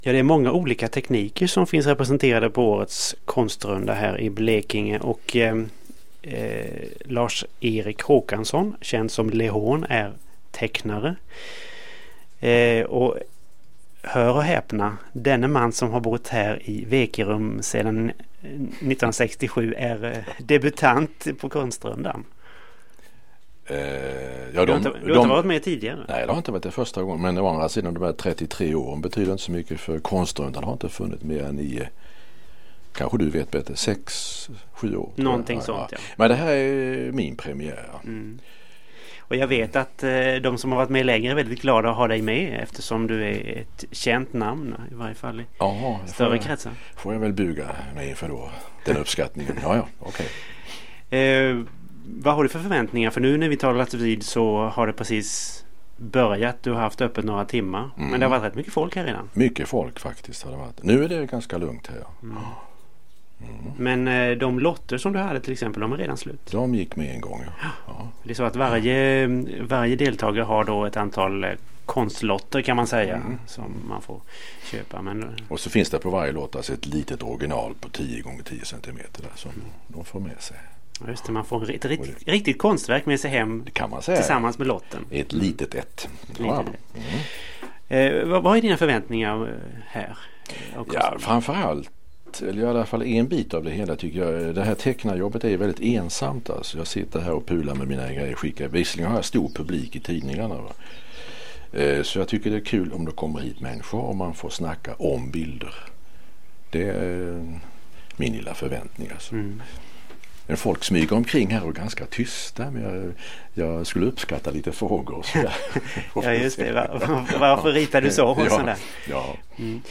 Lehån, den världsberömde satirtecknaren, i en kort intervju under Konstrundan år 2000.